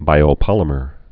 (bīō-pŏlə-mər)